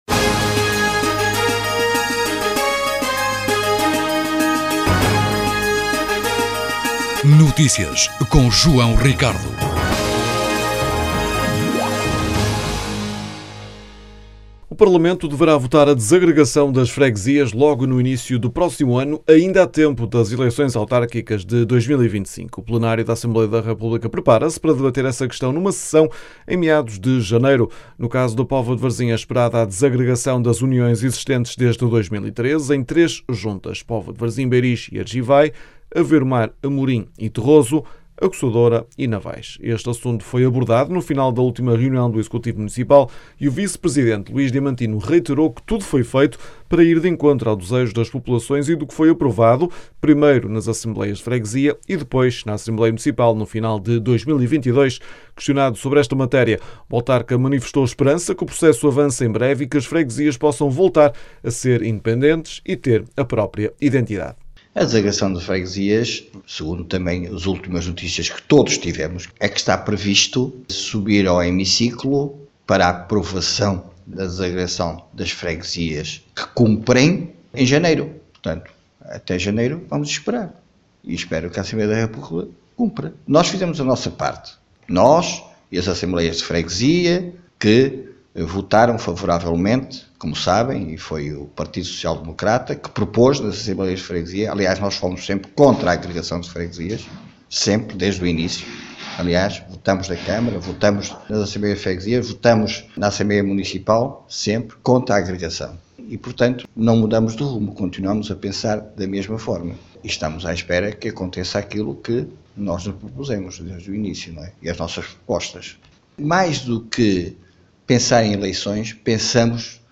Este assunto foi abordado no final da última reunião do executivo municipal e o vice-presidente Luís Diamantino reiterou que tudo foi feito para ir de encontro ao desejo das populações e do que foi aprovado, primeiro nas Assembleias de Freguesia e depois na Assembleia Municipal no final de 2022.
As declarações podem ser ouvidas na edição local.